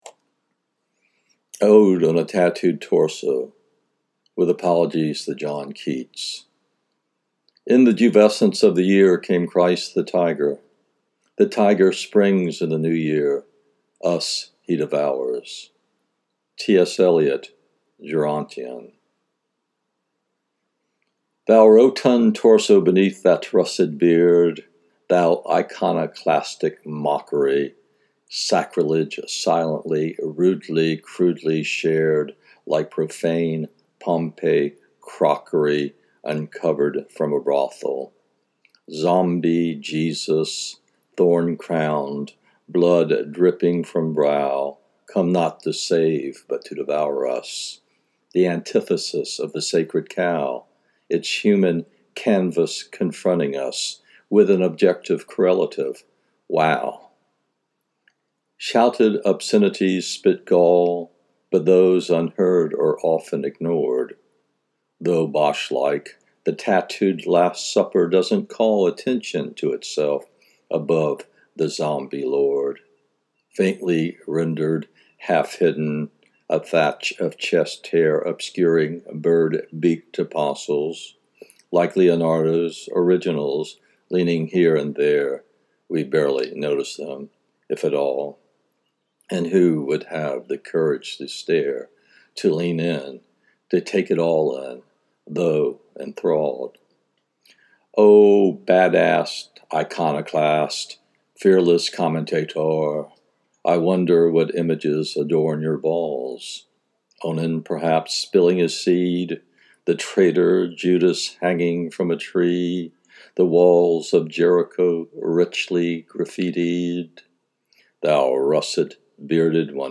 If you decide to read it, I highly recommend hitting the audio and to read it along with my voice.